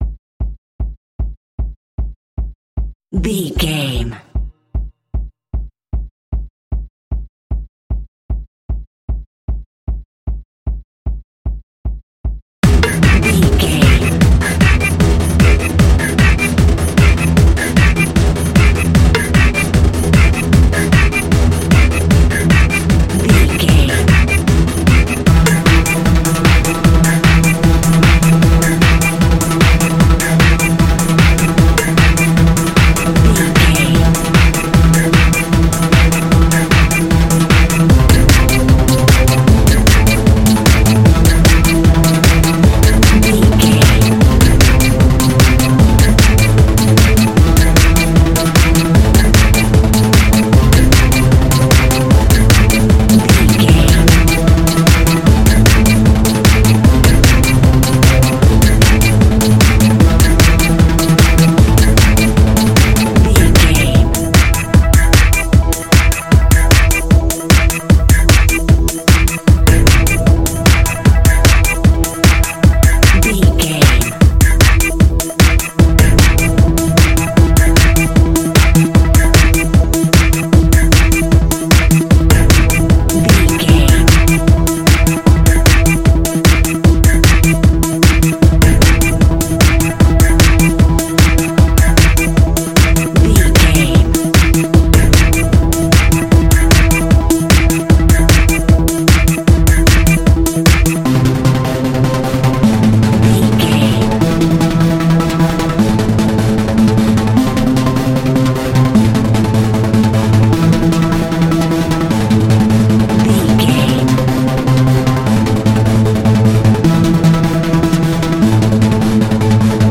Aeolian/Minor
Fast
meditative
futuristic
hypnotic
industrial
mechanical
drum machine
synthesiser
acid house
electronic
uptempo
instrumentals
synth leads
synth bass